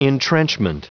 Prononciation du mot entrenchment en anglais (fichier audio)
Prononciation du mot : entrenchment